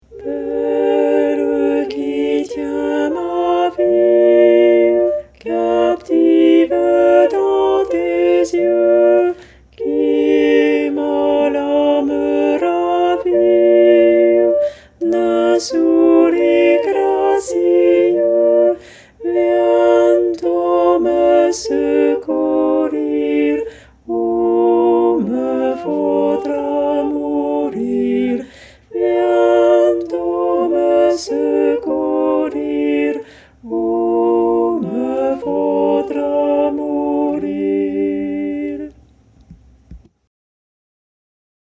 VeRSION CHANTEE
Pavane-Altos.mp3